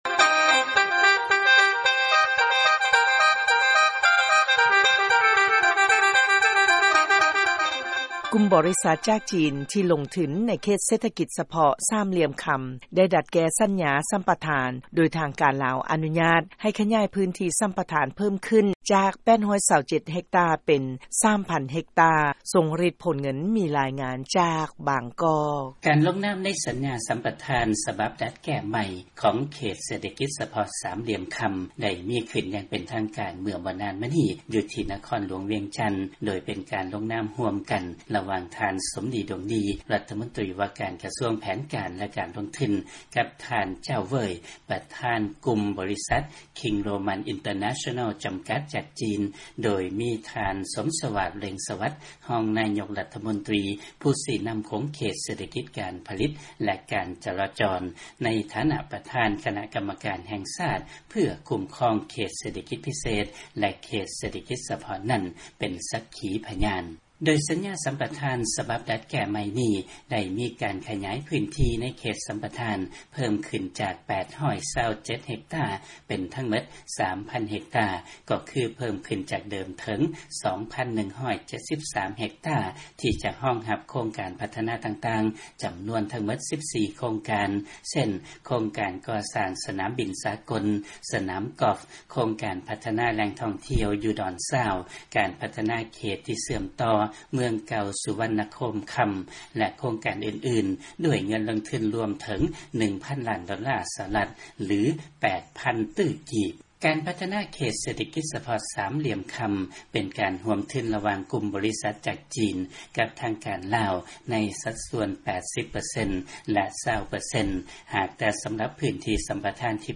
ຟັງລາຍງານ ກຸ່ມບໍລິສັດຈາກຈີນ ທີ່ລົງທຶນ ໃນເຂດເສດຖະກິດ ສາມຫລ່ຽມຄຳ ດັດແກ້ ສັນຍາສຳປະທານໃໝ່.